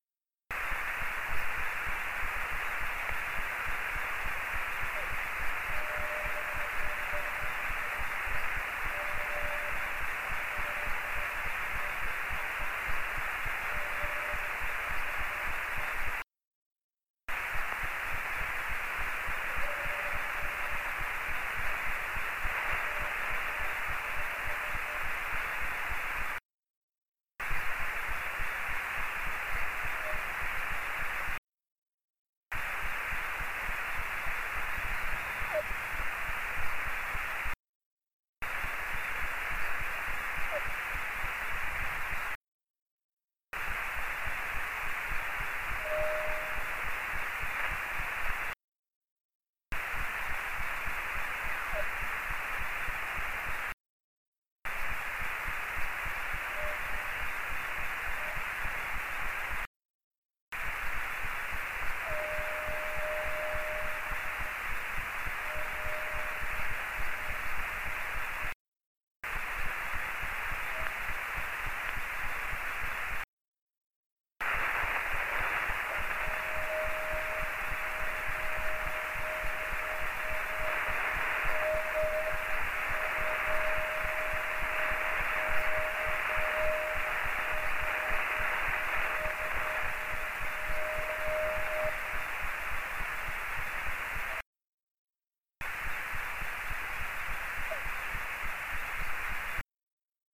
Bíztató pittyegések
A visszaverődés természetesen másról is előfordulhat – így például repülőgépek vagy hőlégballonok is ludasak lehetnek a pittyegésben.
echorovid.mp3